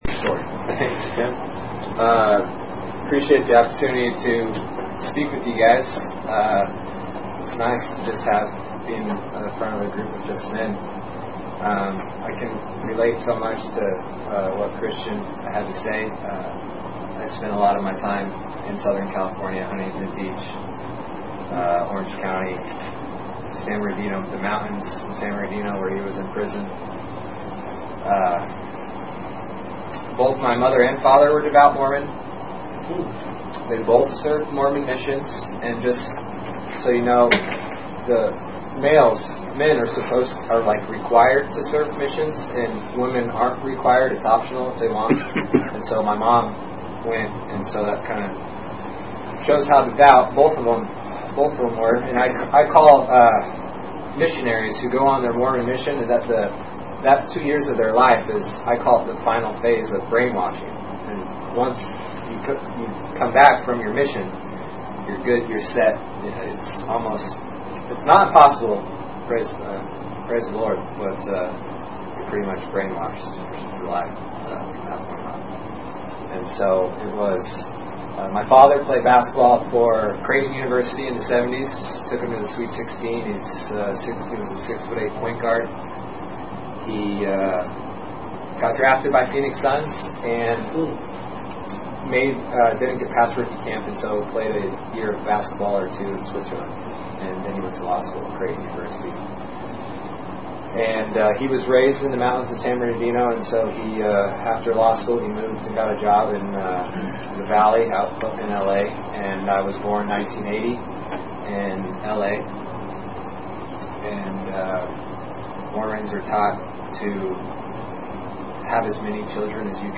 Special Testimony